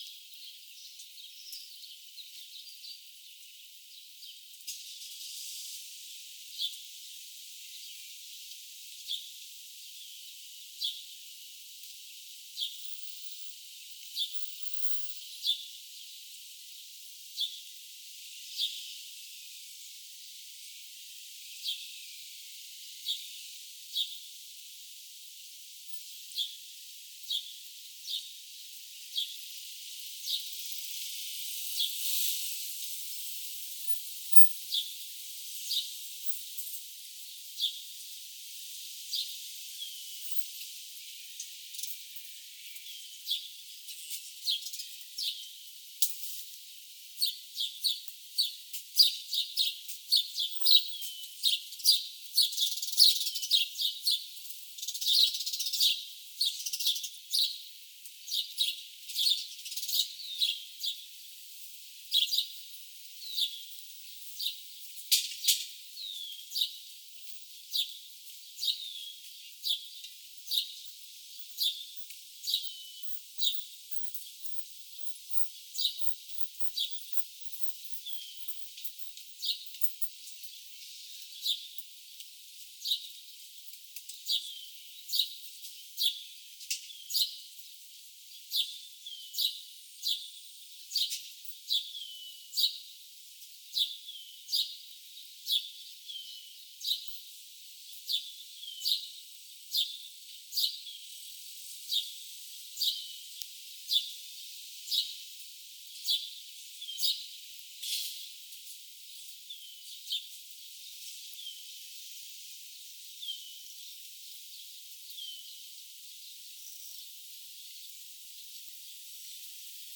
Demonstration soundscapes
307878 | biophony 320505 | transportation 320736 | biophony